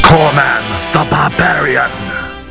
Below is my collection of sounds from the TV show MST3K and MST3K the movie.